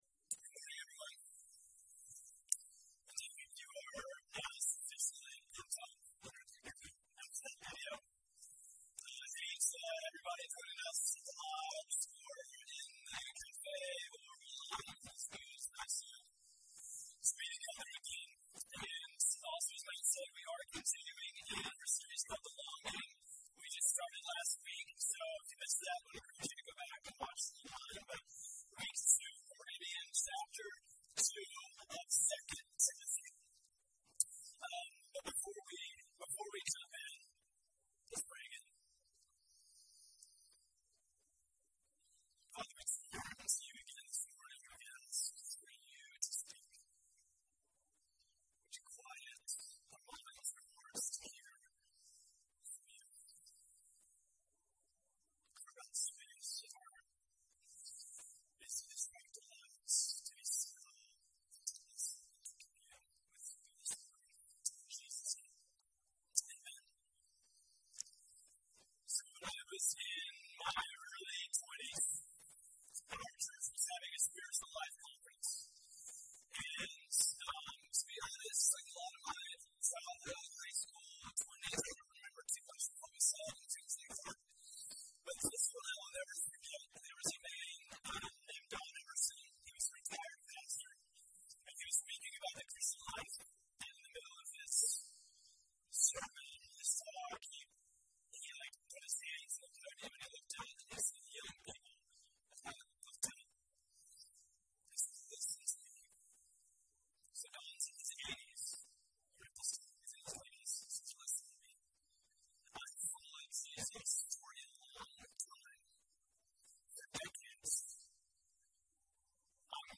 For the next seven weeks, we’ll explore these questions and learn what it means to have life in Christ in our new sermon series, Abundant.